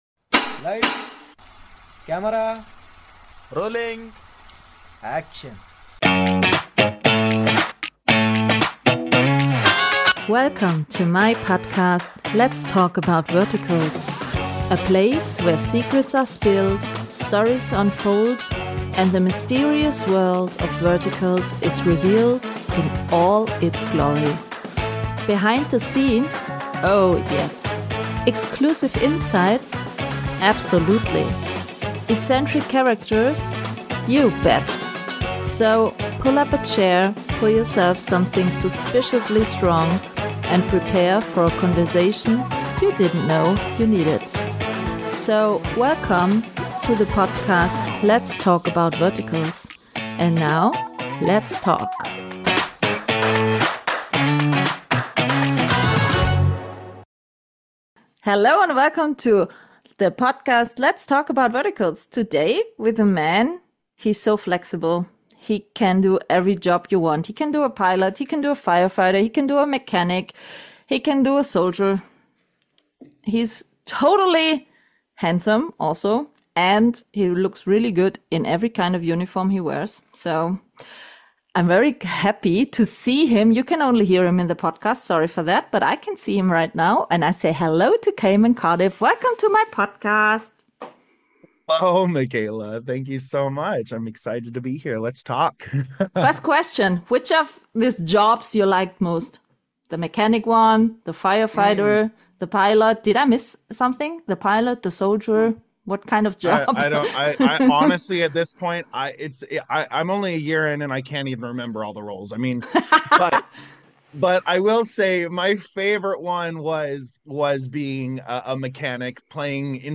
This episode comes with a high risk of sore laughing muscles — seriously, I don’t remember the last time I laughed this hard during a recording.